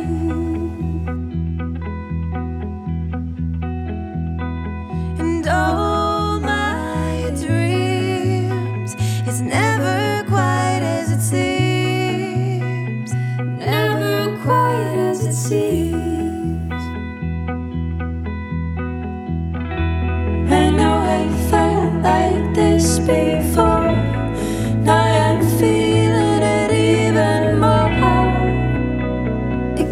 • Contemporary Singer/Songwriter